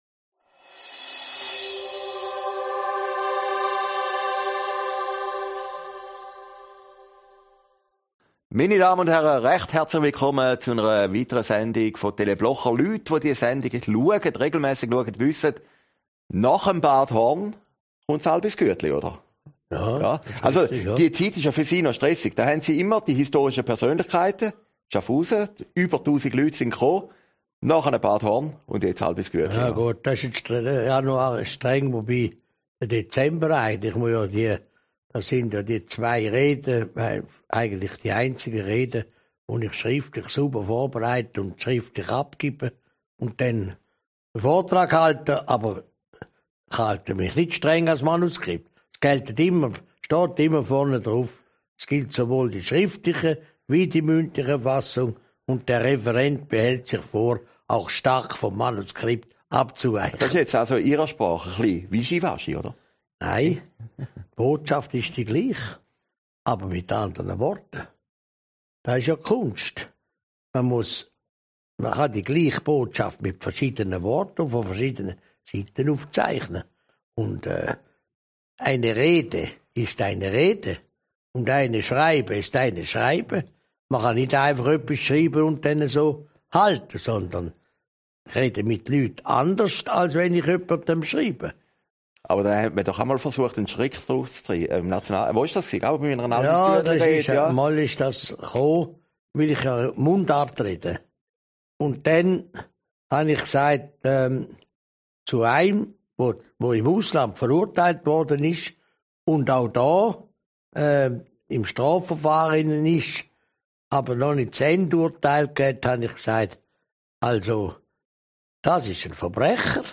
Aufgezeichnet in Herrliberg, 17. Januar 2020